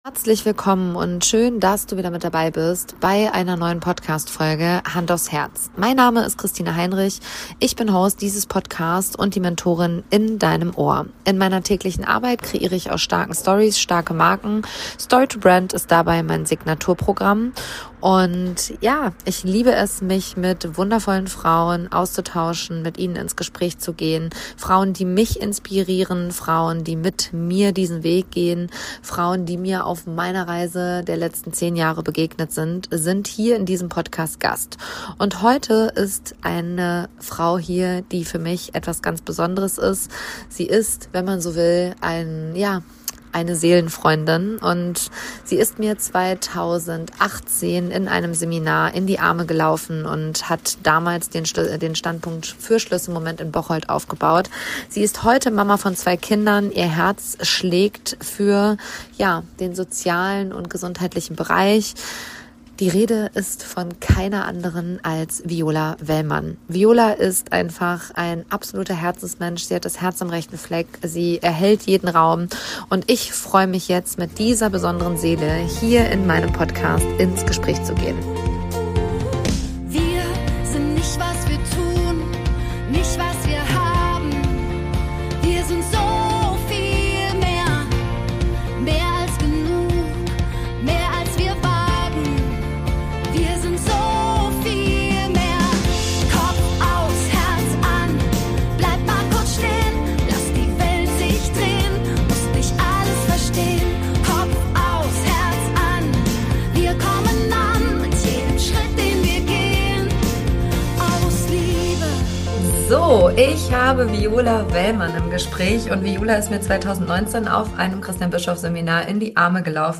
Ein Gespräch über Freundschaft, Entwicklung und darüber, das eigene Leben wirklich zu leben.